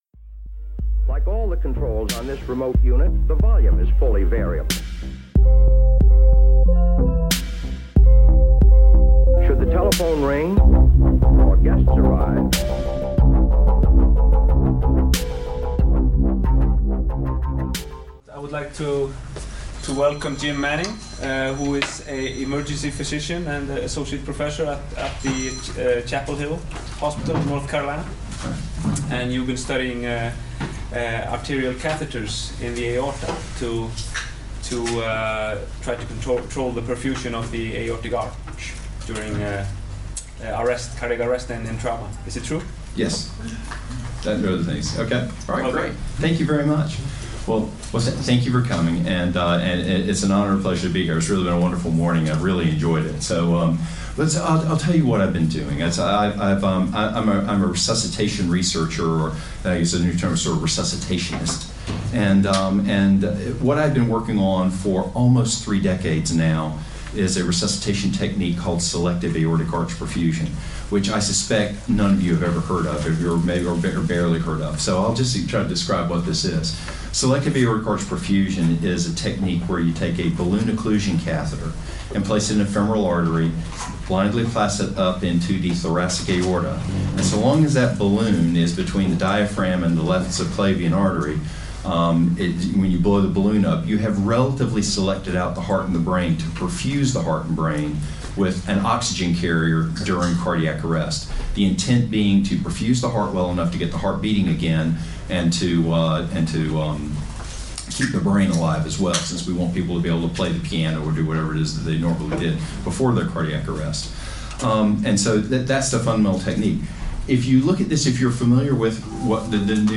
talking at a research meeting at Karolinska in June 2018. The topic was Selective Aortic Arch Perfusion in medical and traumatic cardiac arrest.